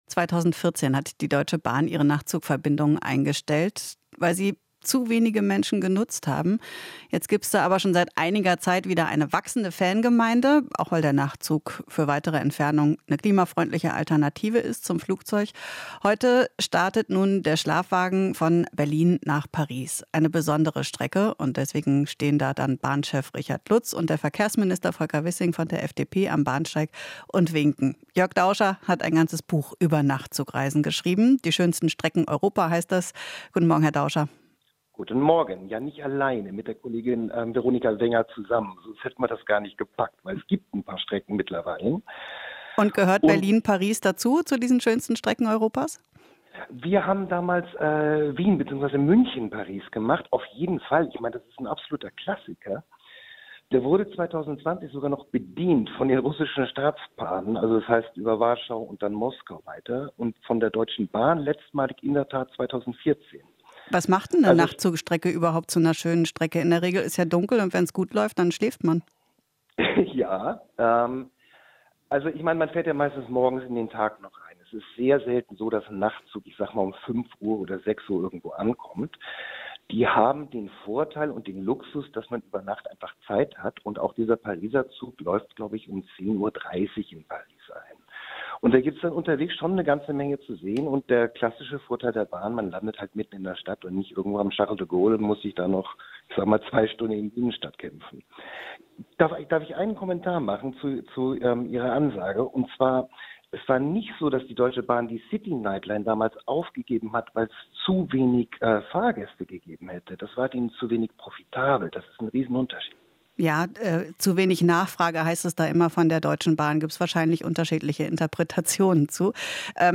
Interview - Neue Verbindung: Mit dem Nachtzug von Berlin nach Paris